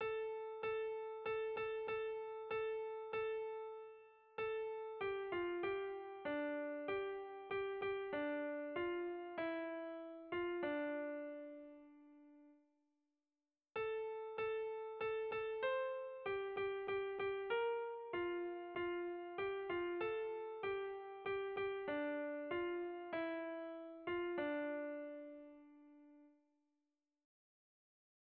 Erlijiozkoa
Lauko handia (hg) / Bi puntuko handia (ip)
A-A2